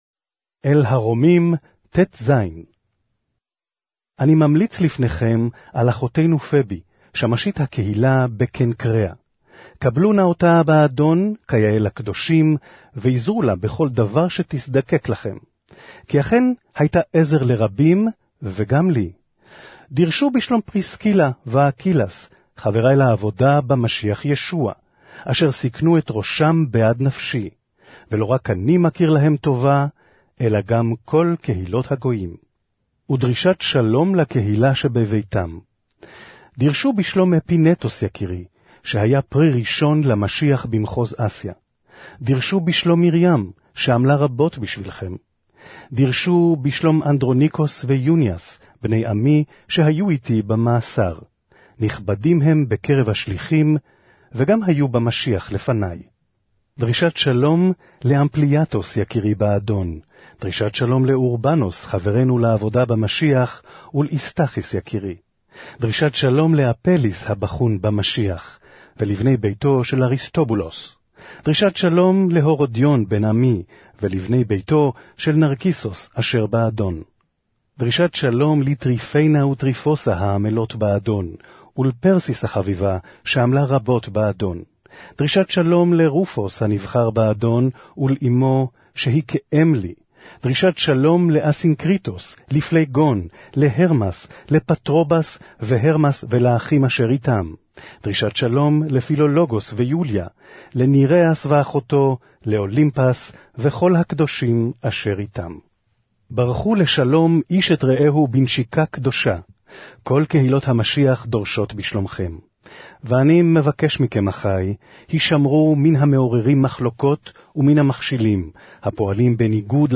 Hebrew Audio Bible - Romans 2 in Ocvml bible version